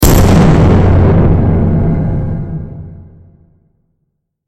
دانلود آهنگ نبرد 15 از افکت صوتی انسان و موجودات زنده
جلوه های صوتی